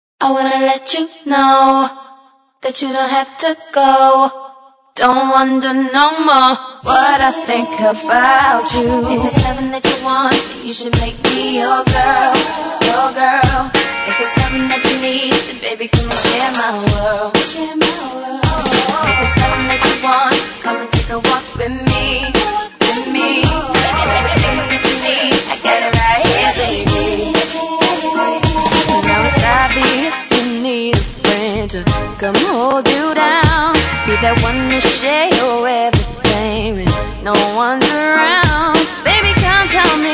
R'n'B